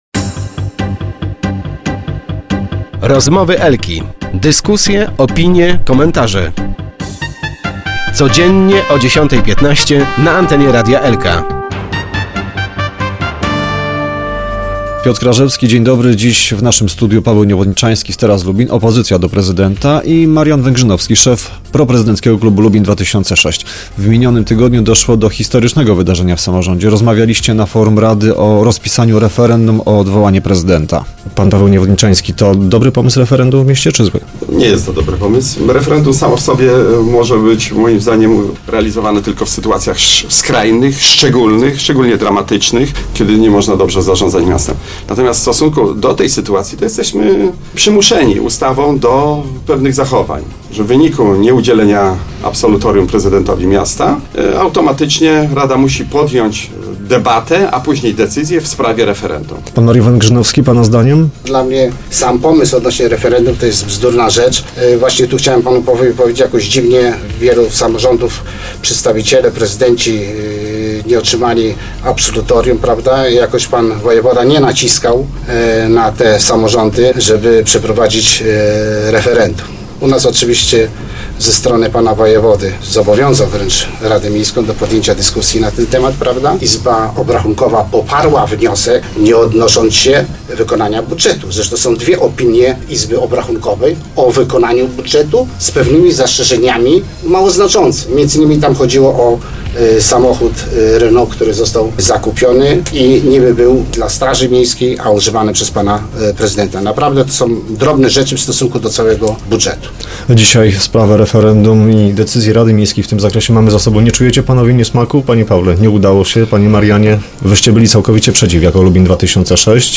Dziś o ostatnich decyzjach rady rozmawialiśmy w naszym lubińskim studio z Marianem Węgrzynowskim z Lubin 2006 i Pawłem Niewodniczańskim z Teraz Lubin.